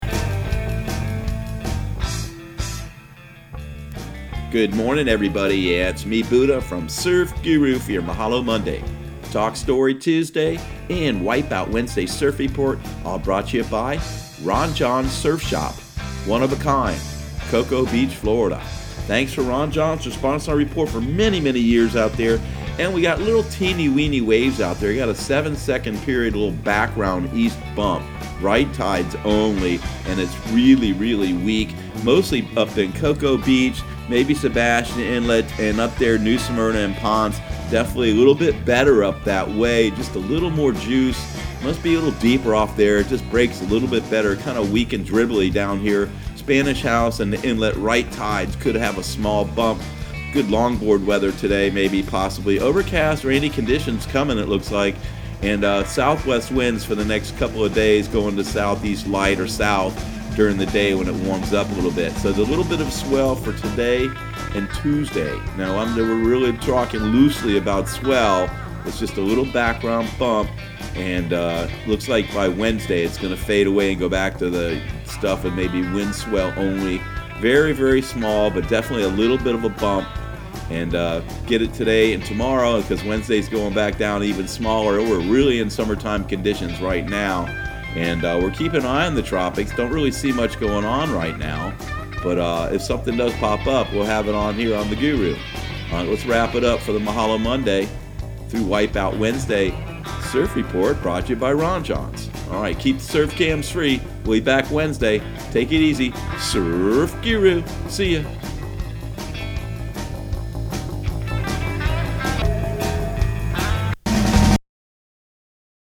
Surf Guru Surf Report and Forecast 06/10/2019 Audio surf report and surf forecast on June 10 for Central Florida and the Southeast.